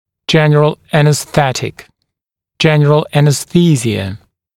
[‘ʤen(ə)r(ə)l ˌænəs’θetɪk] [‘ʤen(ə)r(ə)l ˌænəs’θiːzɪə][‘джэн(э)р(э)л ˌэнэс’сэтик] [‘джэн(э)р(э)л ˌэнэс’си:зиэ]общая анестезия